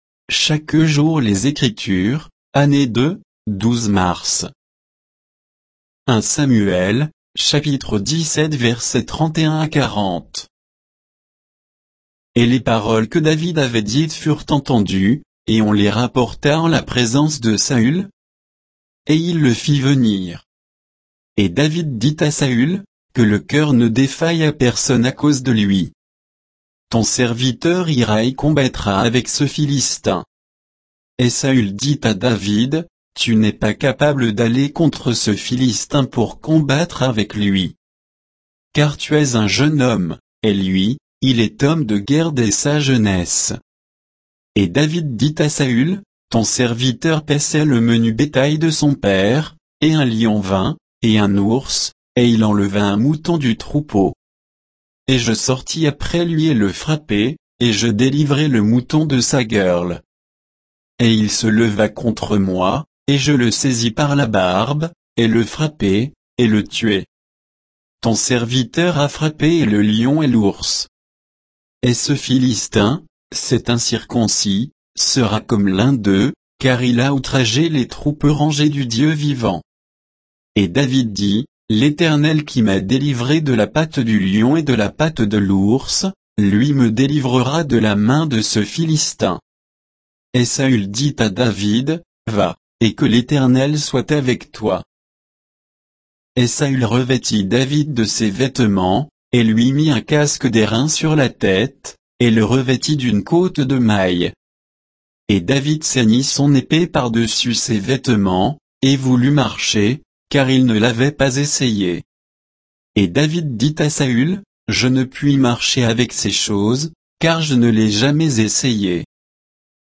Méditation quoditienne de Chaque jour les Écritures sur 1 Samuel 17, 31 à 40